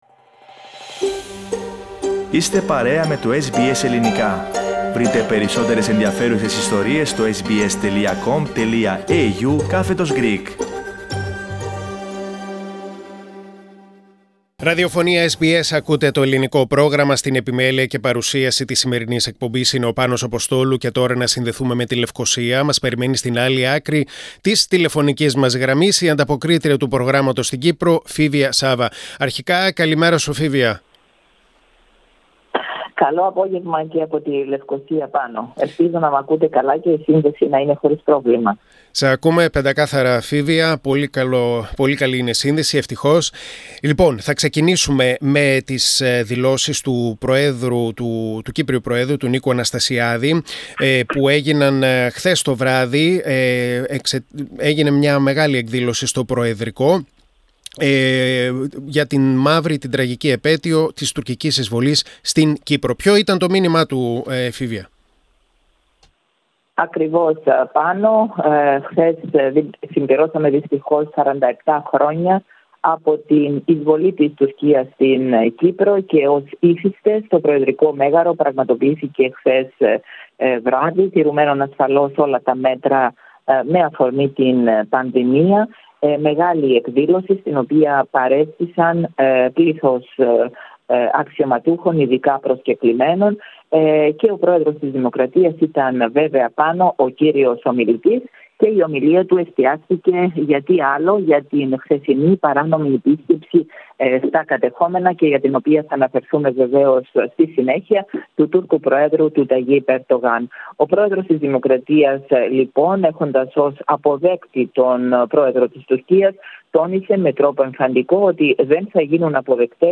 Ειδήσεις και νέα από την Κύπρο στην εβδομαδιαία ανταπόκριση από την Λευκωσία (21.07.2021)